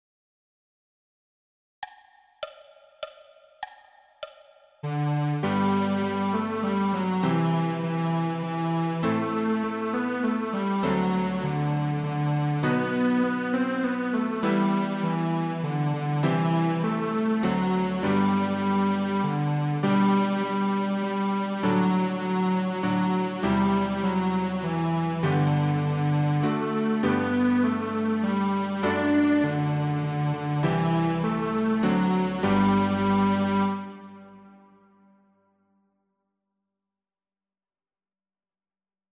We-wish-you-a-merry-christmas-cello-and-piano.mp3